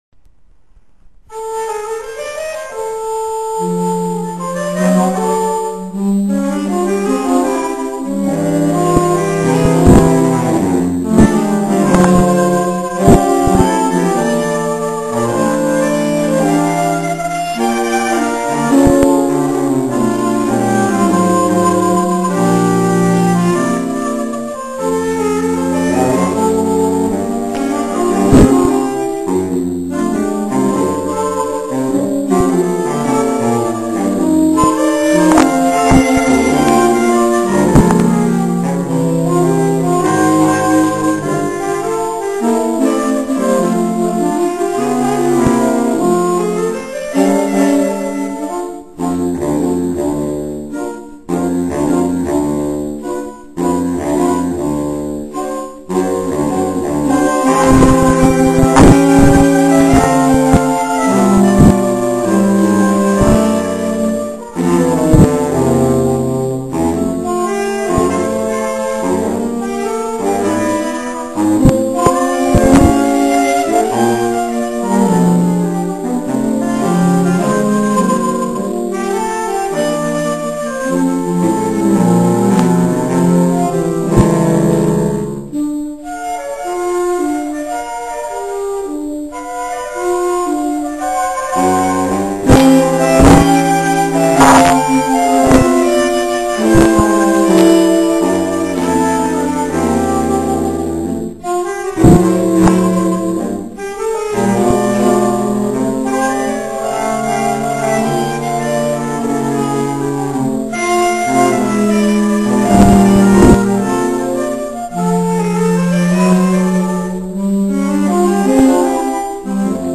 Saxofonové kvarteto Moravia
26. září 2006 - 23. komorní koncert na radnici - Podrobný program koncertu "Melodie dýchajícího kovu"
sopránový saxofon
altový saxofon
tenorový saxofon
barytonový saxofon
Ukázkové amatérské nahrávky WMA: